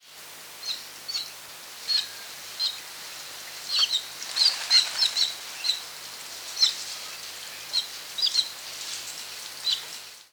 Hören Sie sich hier die Stimme der Trottellumme an:
Laute der Trottellumme
1553-trottellumme_laute-soundarchiv.com_.mp3